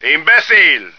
flak_m/sounds/male2/est/M2sucker.ogg at d2951cfe0d58603f9d9882e37cb0743b81605df2